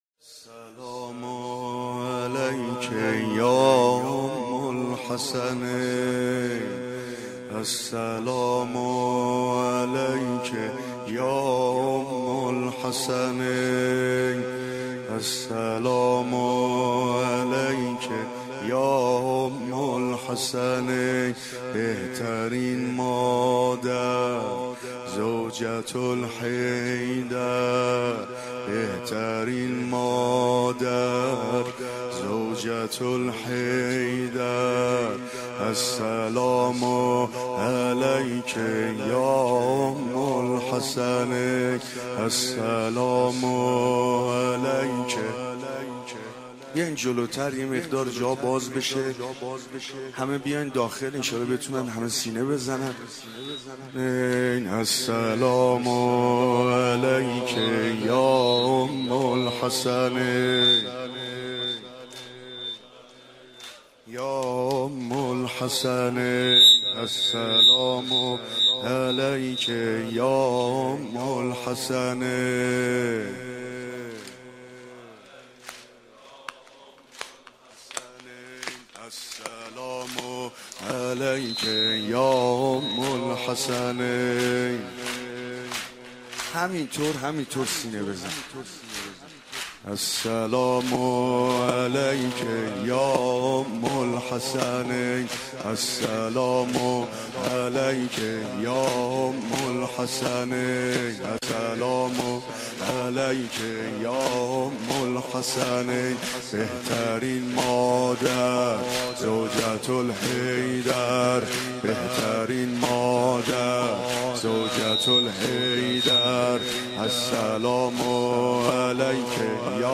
دانلود مداحی آبروی روز محشر - دانلود ریمیکس و آهنگ جدید